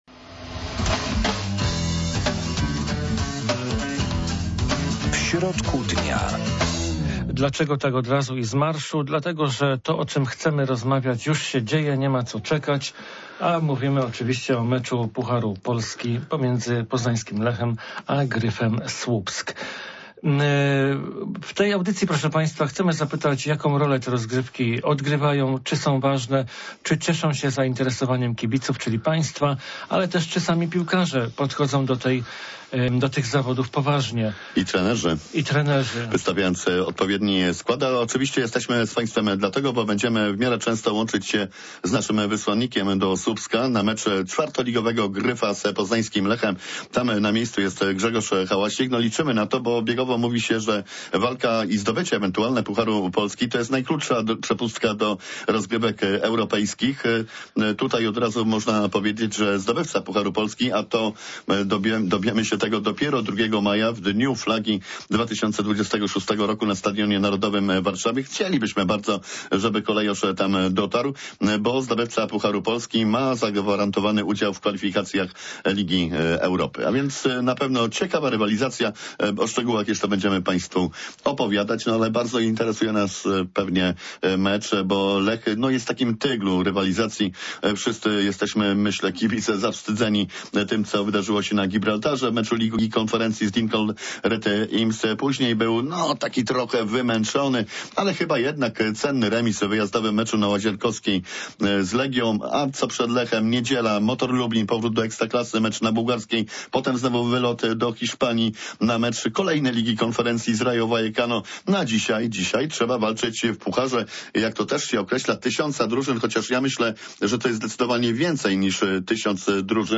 Puchar Polski w piłce nożnej - ważne rozgrywki czy balast dla klubu. W studio